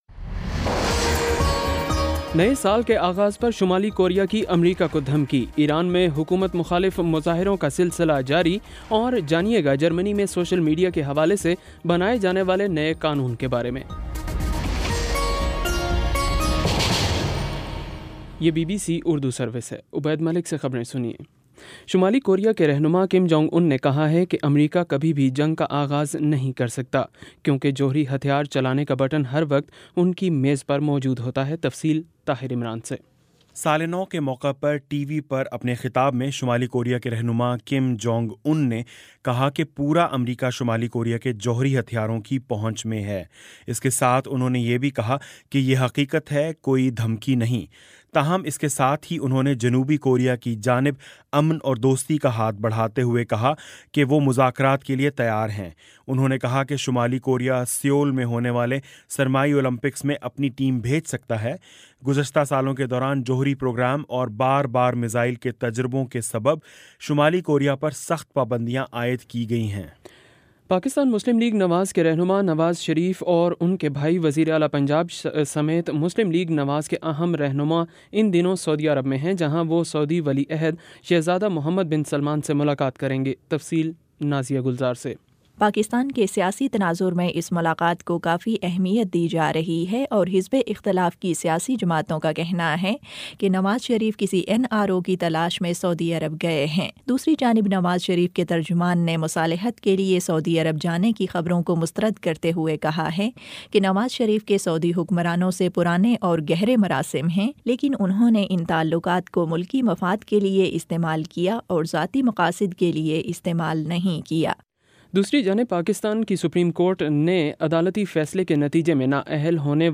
جنوری 01 : شام پانچ بجے کا نیوز بُلیٹن